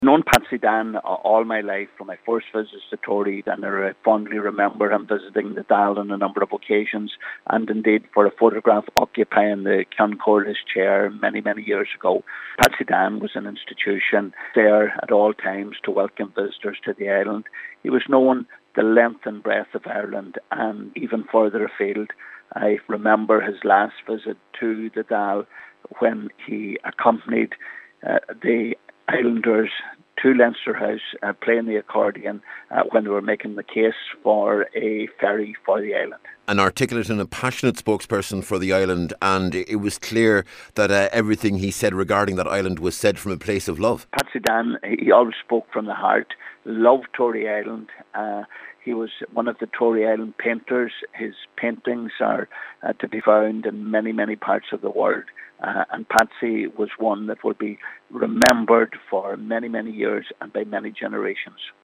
Leas Ceann Comhairle and former Minister Pat The Cope Gallagher says Patsy Dan Rogers was a passionate and articulate advocate for Tory………….